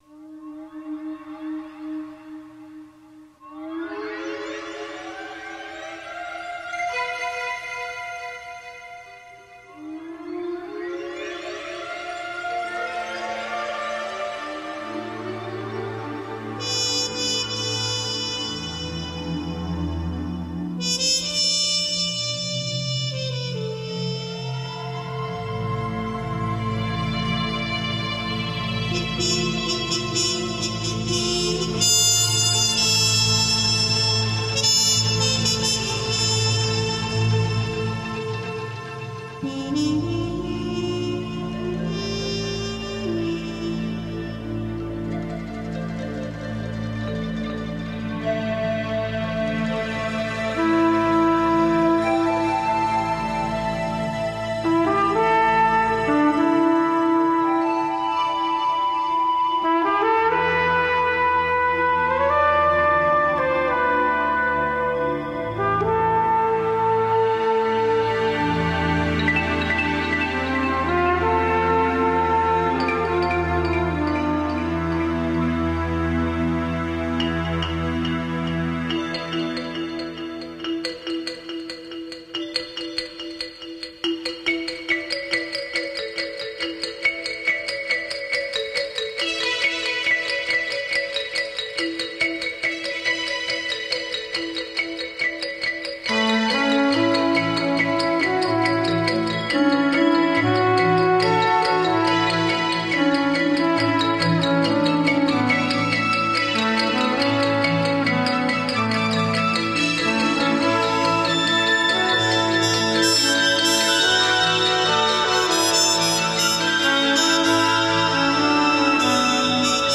Here's one I started on but didn't finish (and obviously still trying to define a melody).
This track was done using a Korg 01/w and a trumpet. I used Brusfri to get rid of tape hiss, TB compressor, TB EQ, MixBox, Beatformer to punch it up. Already had too much reverb in it, but I couldn't resist adding a touch more, lol.